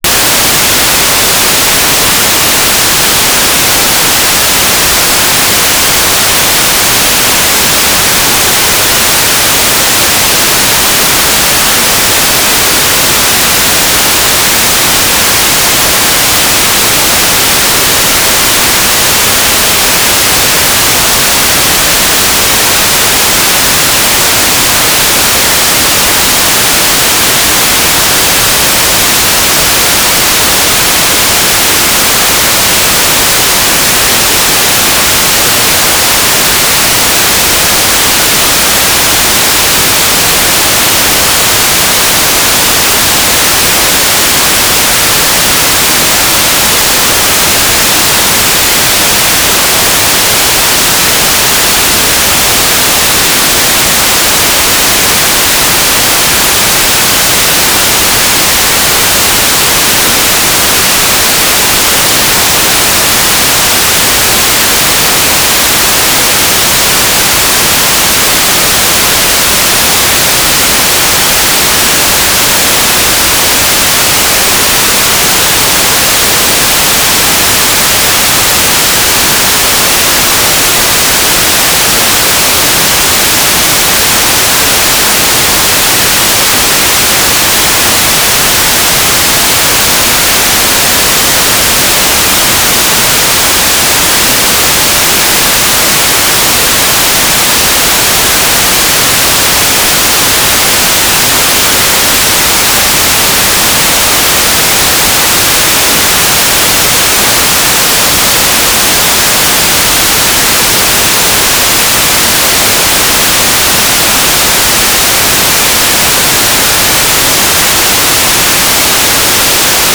"transmitter_description": "Mode U - GMSK2k4 - USP",
"transmitter_mode": "GMSK USP",